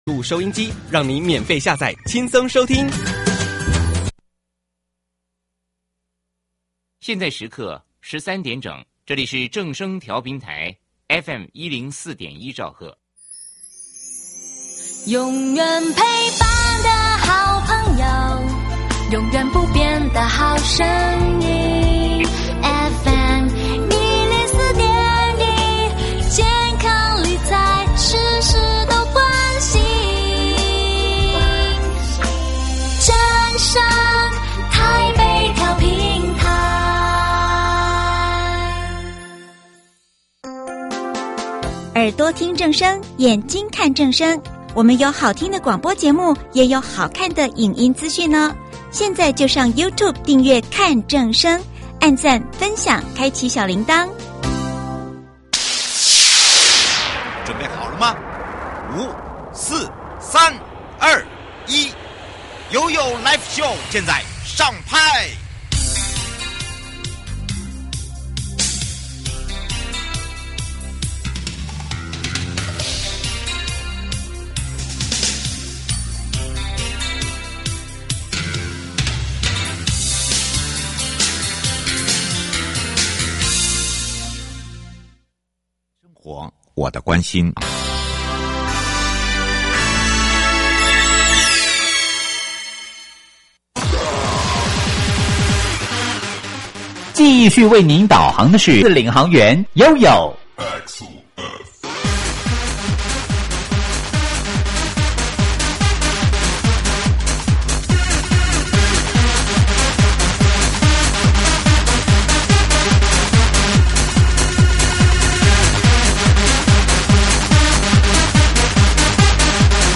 受訪者： 營建你我他 快樂平安行~七嘴八舌講清楚~樂活街道自在同行!(三) 今天，我們要帶大家一起了解桃園市如何透過資料盤點、跨局合作與工程優化，針對92處行人熱點路口進行改善，推動以人為本的道路安全環境。